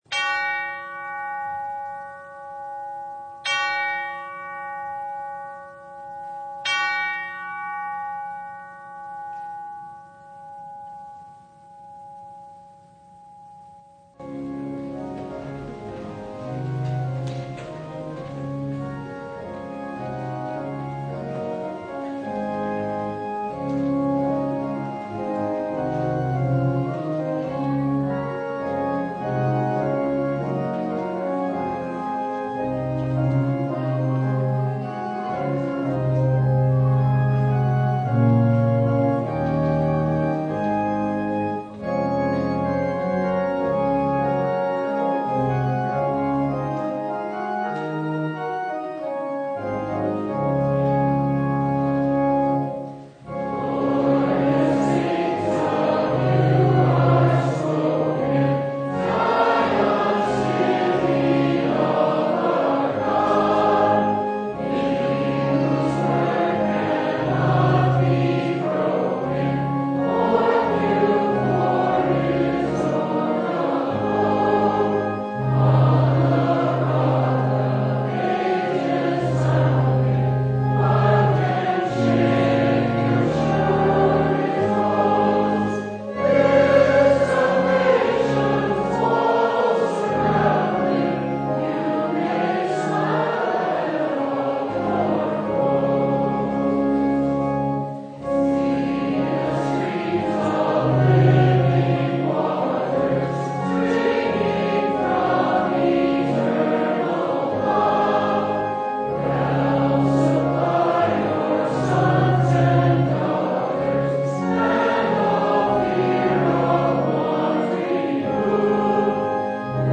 Service Type: Sunday
Download Files Bulletin Topics: Full Service « “Take Heart; It Is I!”